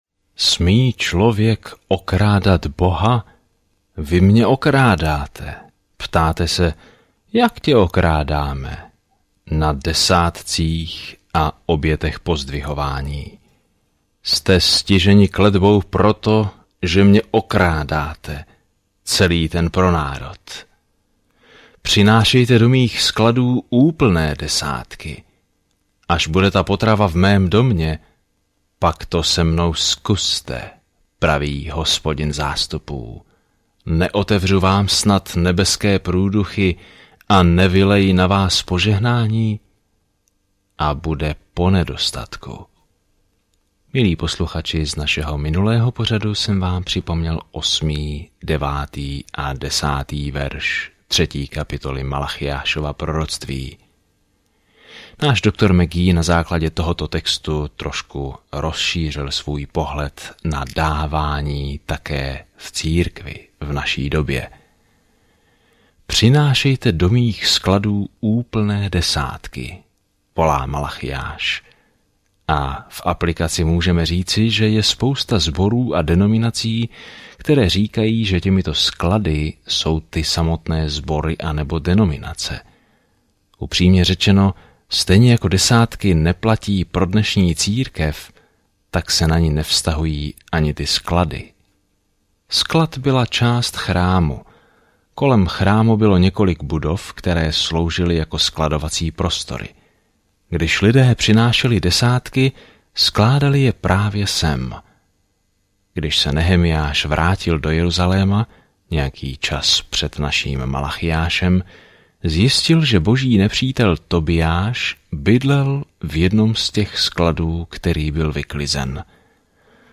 Písmo Malachiáš 3:11-16 Den 12 Začít tento plán Den 14 O tomto plánu Malachiáš připomíná odpojenému Izraeli, že má poselství od Boha, než vydrží dlouhé ticho – které skončí, když na scénu vstoupí Ježíš Kristus. Denně procházejte Malachiášem, když posloucháte audiostudii a čtete vybrané verše z Božího slova.